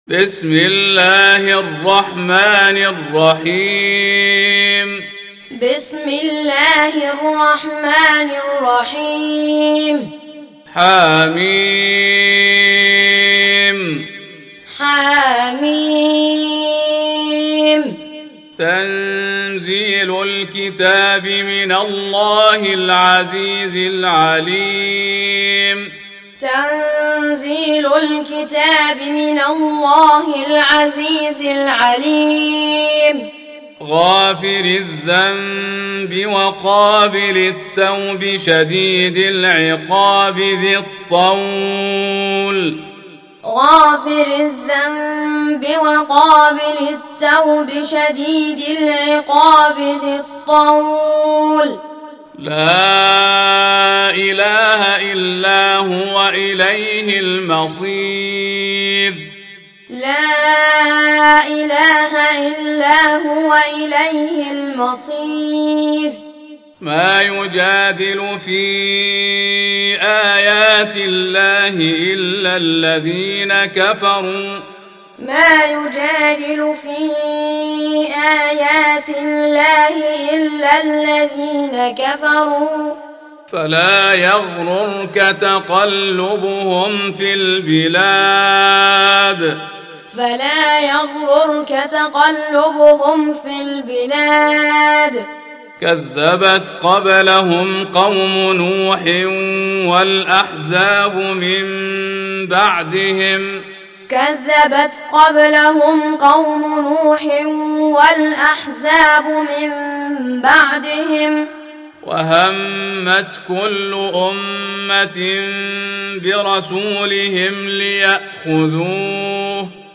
40. Surah Gh�fir سورة غافر Audio Quran Taaleem Tutorial Recitation Teaching Qur'an One to One